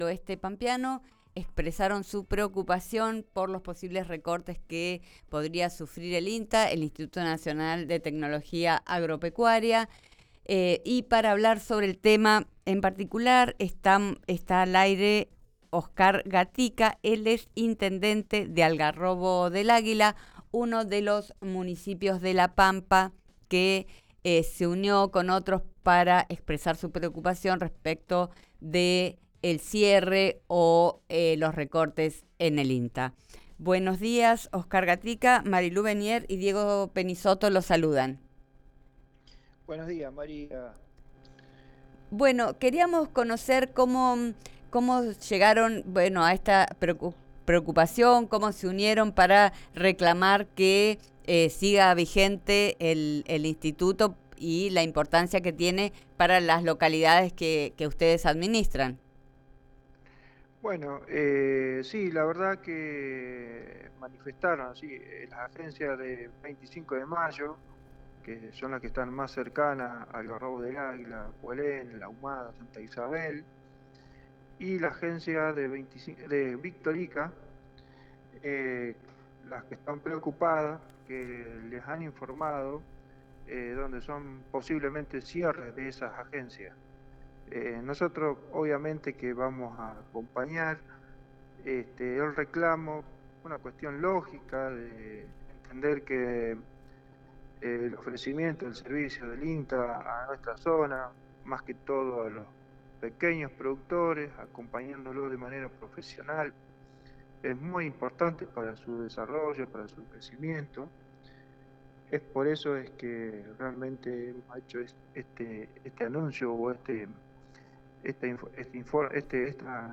Oscar Gatica, de Algarrobo del Águila, conversó con ''Vos Al Aire'', explicó el papel que juega el organismo nacional para los pequeños productores y le reclamó al gobierno nacional que distribuya los recursos de manera federal.